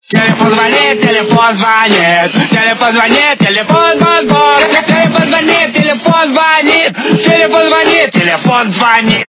» Звуки » Люди фразы » Голос - Телефон звонит, телефон звонит
При прослушивании Голос - Телефон звонит, телефон звонит качество понижено и присутствуют гудки.
Звук Голос - Телефон звонит, телефон звонит